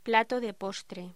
Locución: Plato de postre